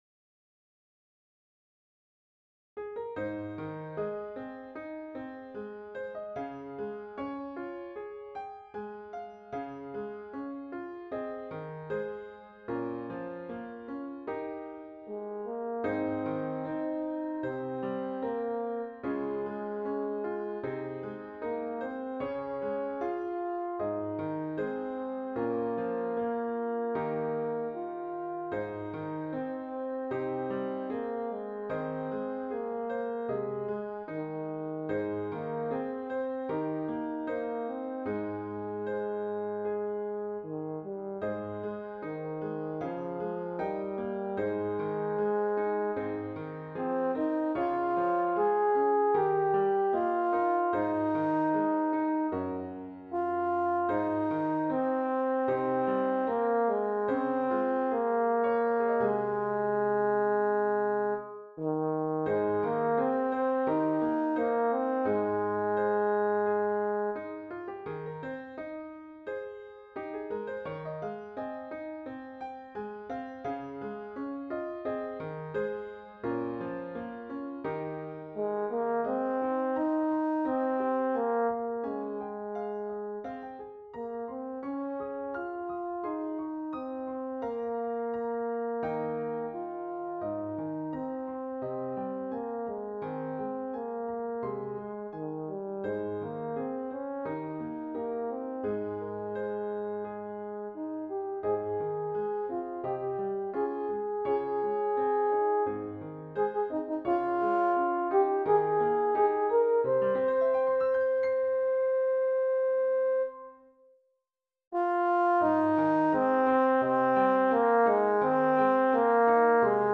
Intermediate Instrumental Solo with Piano Accompaniment.
Christian, Gospel, Sacred, Folk.
A Hymn arrangement
put to a flowing folk setting.